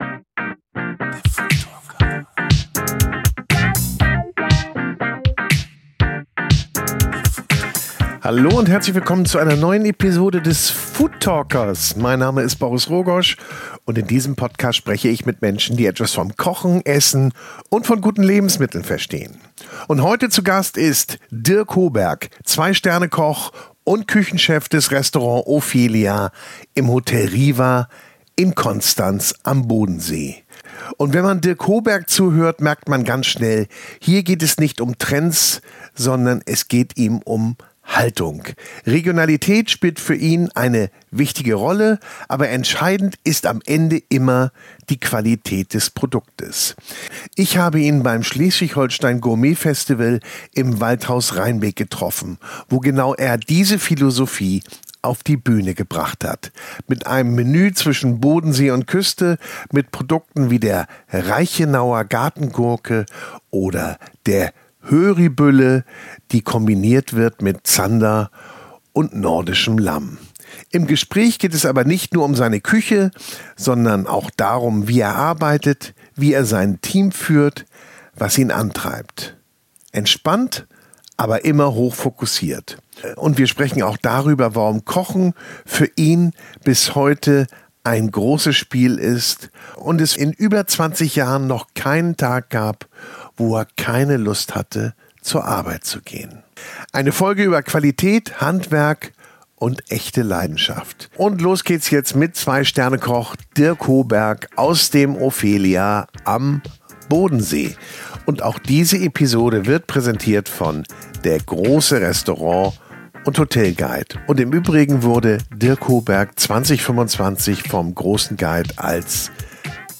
Ich treffe ihn beim Schleswig-Holstein Gourmet Festival im Waldhaus Reinbek.